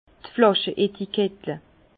Prononciation 67 Herrlisheim